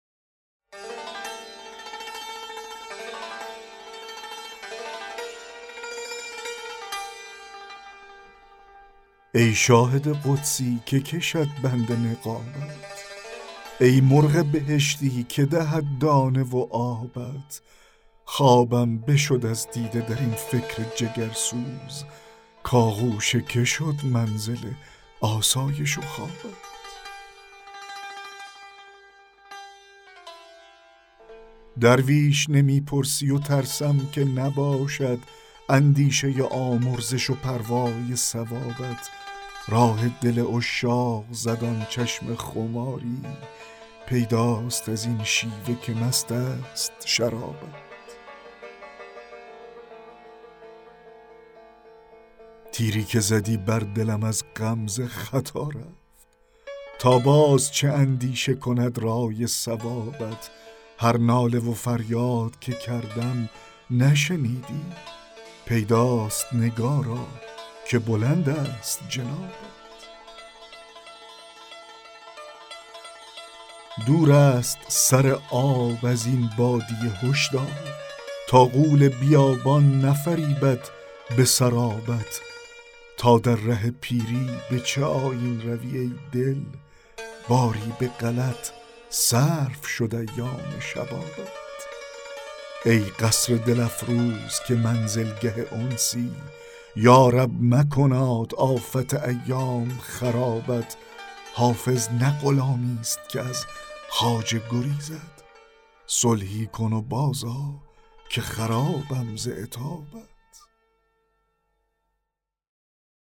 دکلمه غزل 15 حافظ
دکلمه-غزل-15-حافظ-ی-شاهد-قدسی-که-کشد-بند-نقابت.mp3